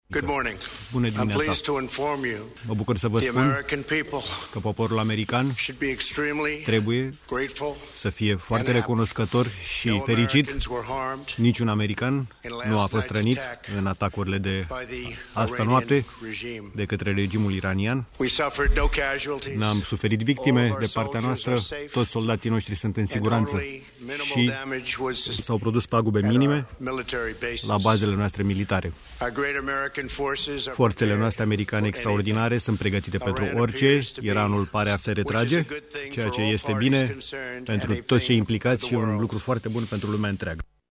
A  spus preşedintele Statelor Unite, într-o declaraţie de presă făcută la Casa Albă: